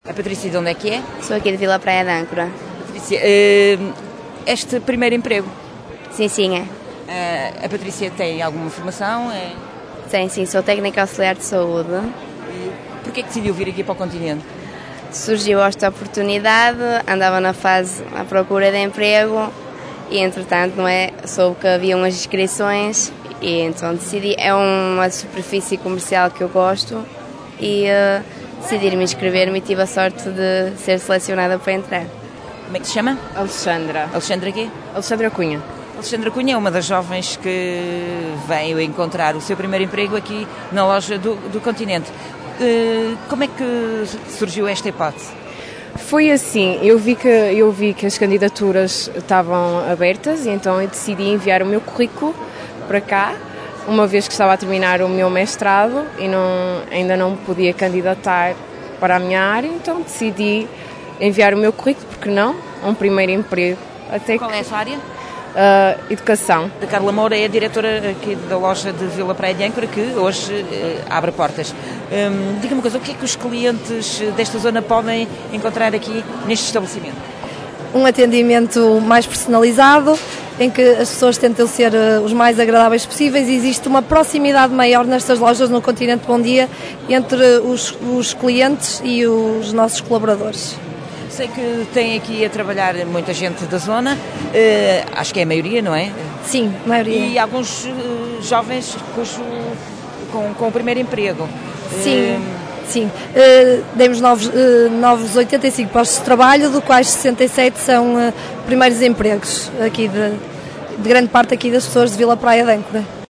A Rádio Caminha ouviu alguns desses jovens que vão começar a dar os primeiros passos no mundo do trabalho dentro daquela superfície comercial.